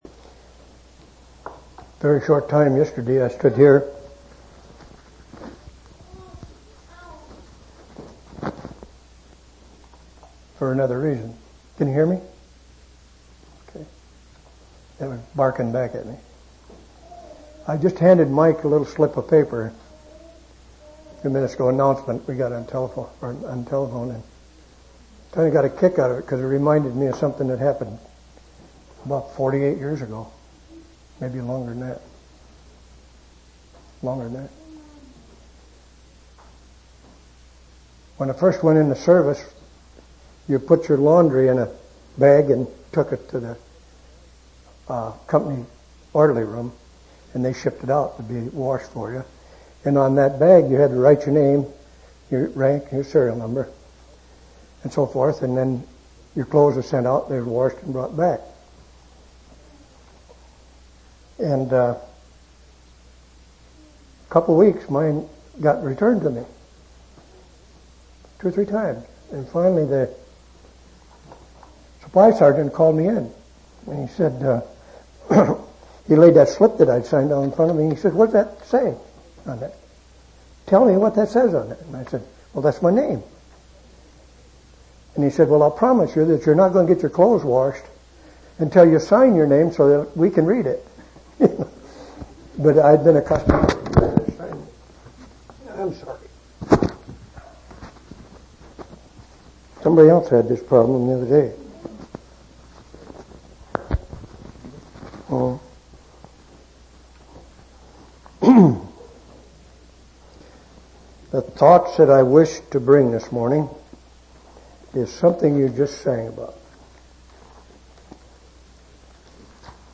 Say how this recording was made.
9/27/1992 Location: East Independence Local Event